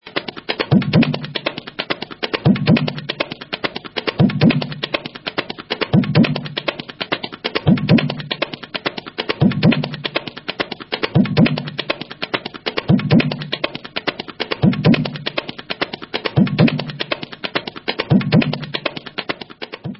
Tabla Tirkit